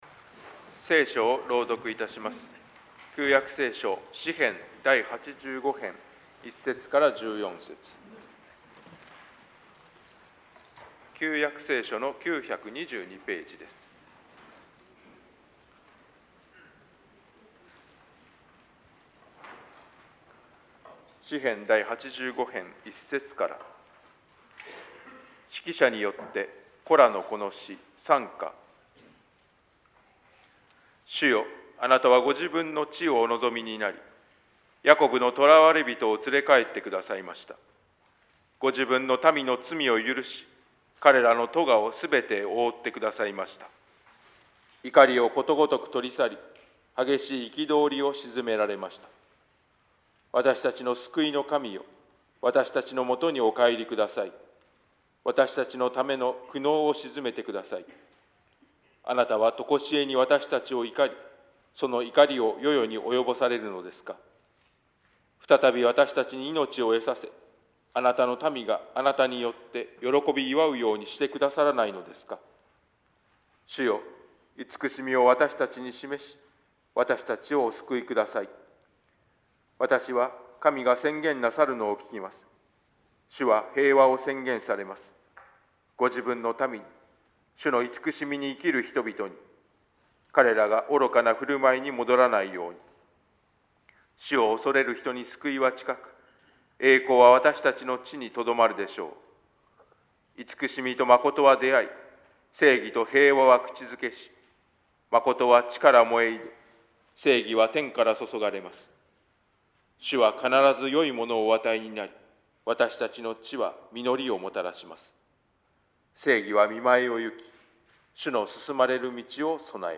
説 教